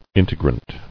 [in·te·grant]